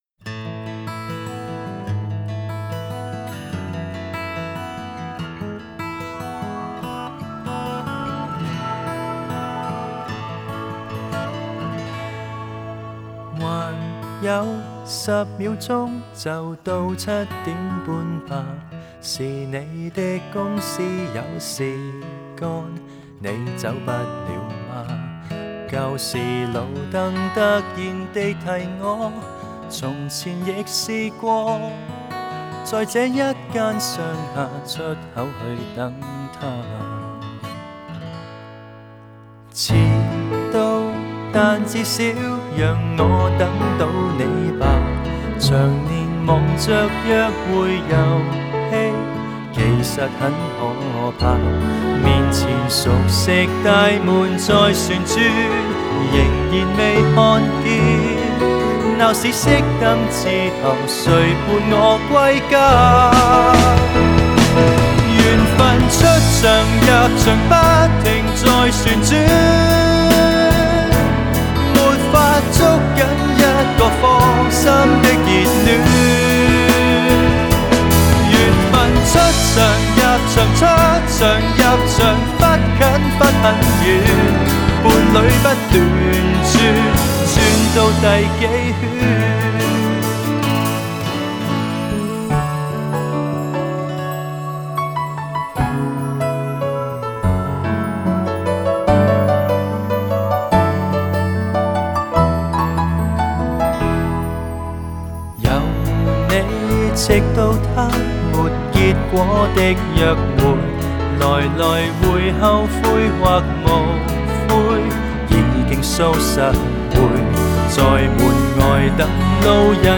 Ps：在线试听为压缩音质节选，体验无损音质请下载完整版 还有十秒钟就到七点半吧 是你的公司有事干 你走不了吗?